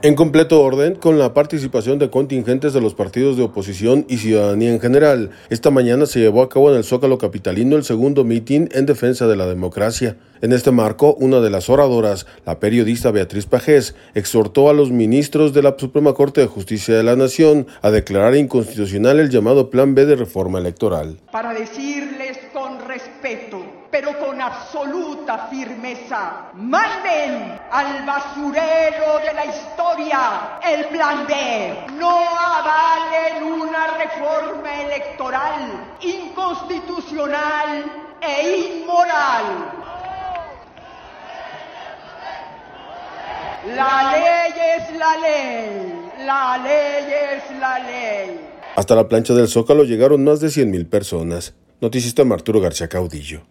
En completo orden, con la participación de contingentes de los partidos de oposición y ciudadanía en general, esta mañana se llevó a cabo en el zócalo capitalino el segundo mitin en defensa de la democracia.